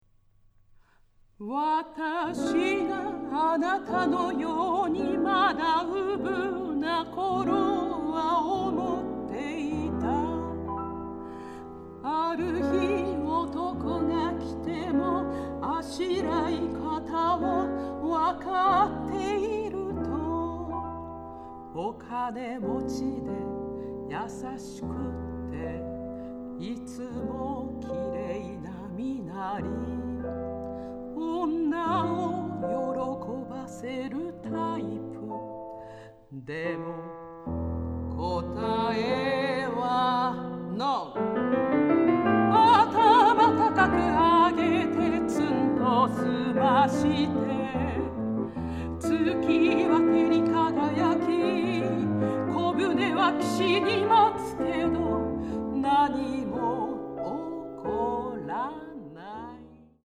ヒリヒリとした緊張感と童謡のように優しく歌に包まれる感覚が同居していて◎！